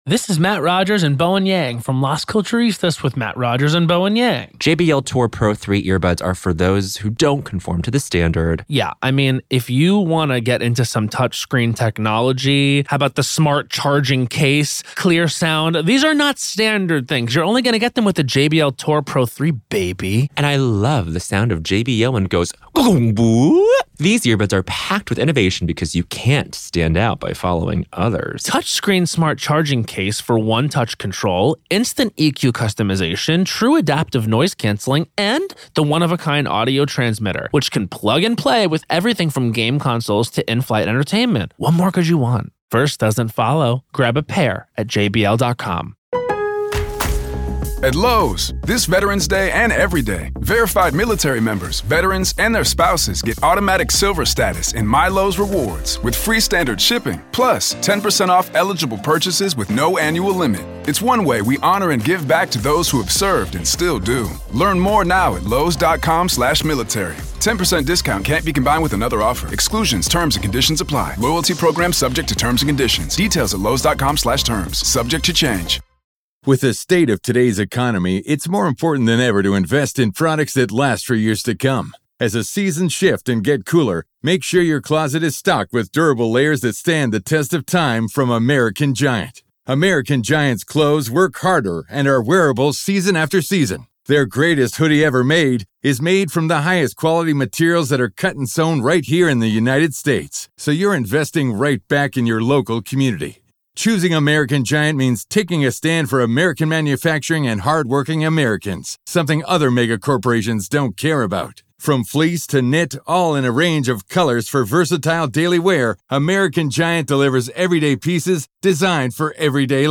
RAW COURT AUDIO-Karen Read: Post-Jury Selection Court Hearing-PART 1